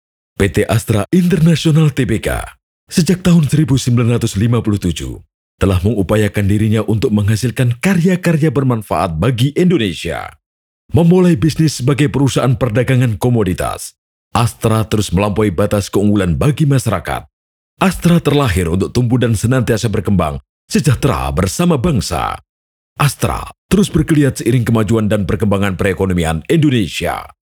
Casual Documentaries Educational Formal